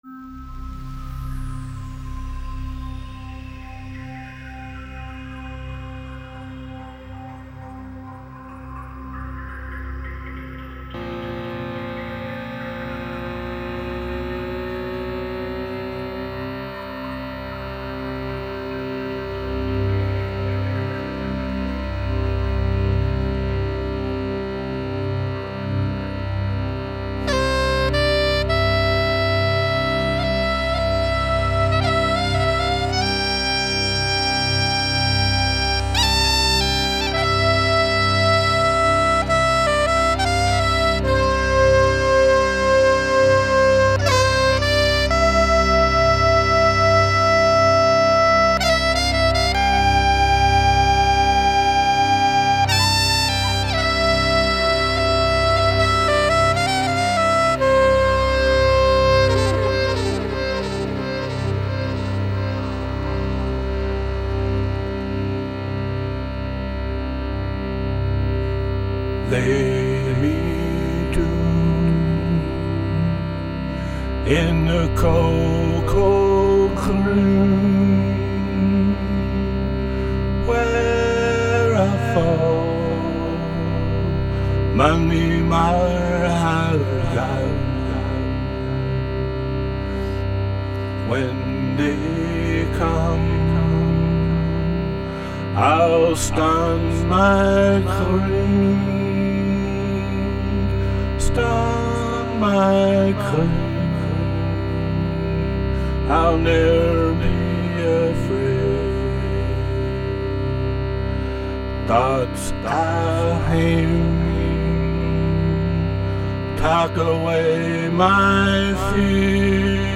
Soprano Saxophone